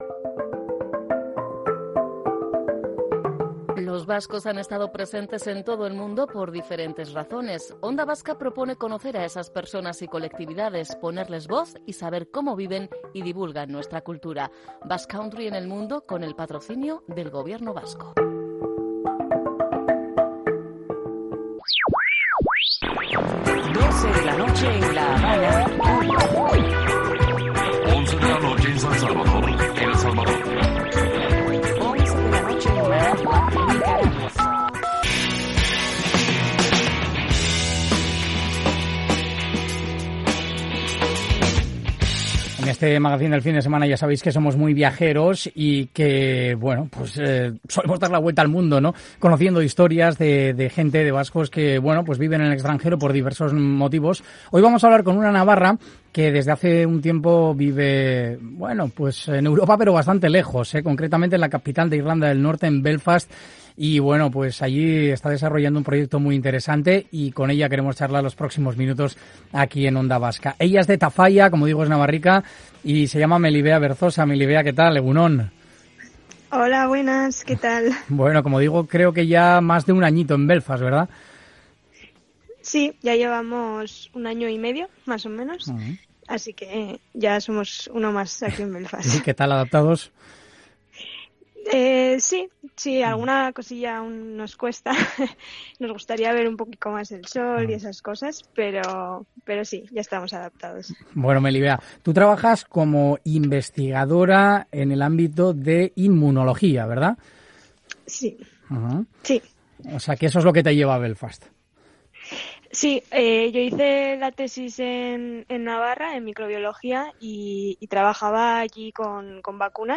Onda Vasca Bizkaia en directo